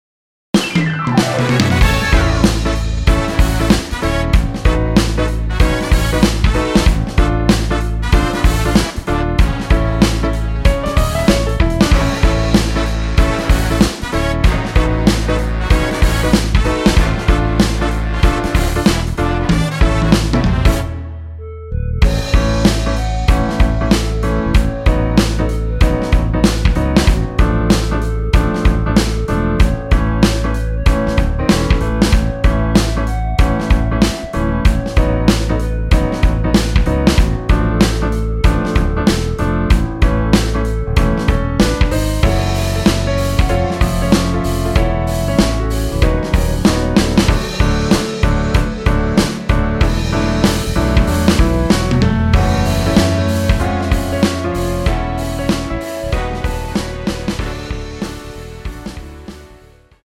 원곡보다 짧은 MR입니다.(아래 재생시간 확인)
원키에서(-5)내린 (1절앞+후렴)으로 진행되는 멜로디 포함된 MR입니다.
앞부분30초, 뒷부분30초씩 편집해서 올려 드리고 있습니다.
중간에 음이 끈어지고 다시 나오는 이유는